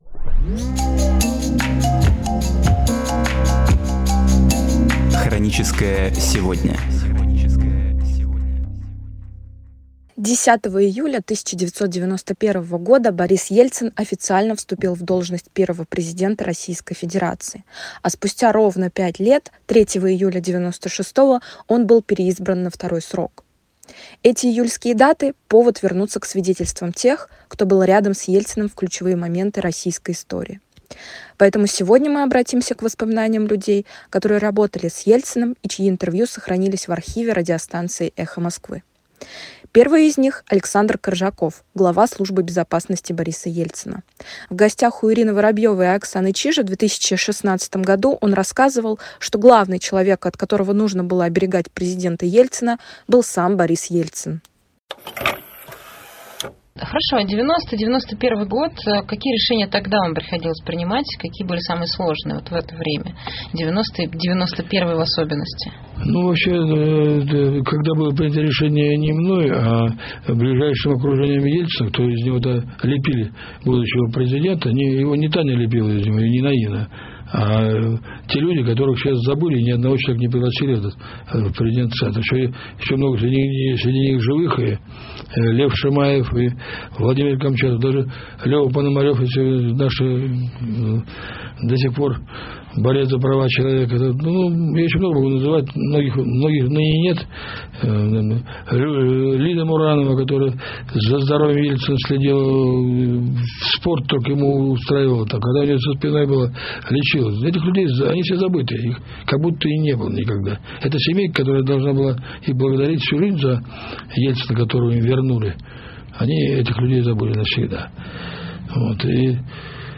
Эти июльские даты — повод вернуться к свидетельствам тех, кто был рядом с Ельциным в ключевые моменты российской истории. Поэтому сегодня мы обратимся к воспоминаниям людей, которые работали с Ельциным и чьи интервью сохранились в архиве радиостанции «Эхо Москвы».